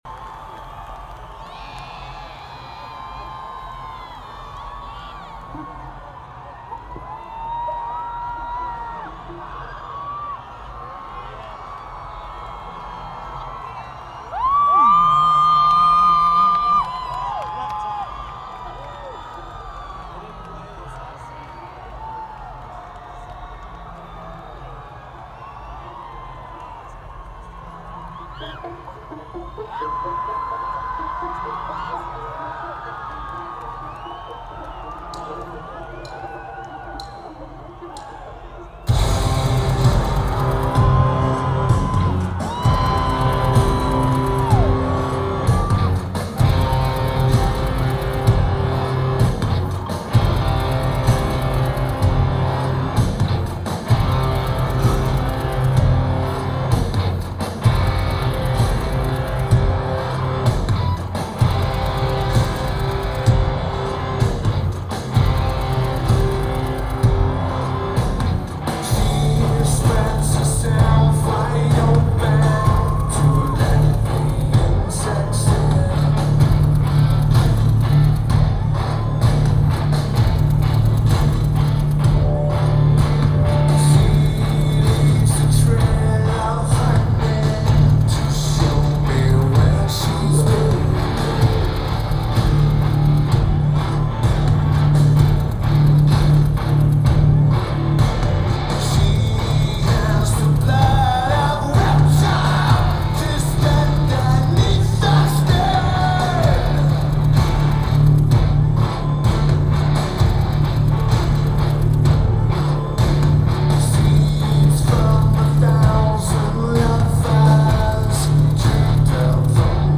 Red Rocks Amphitheatre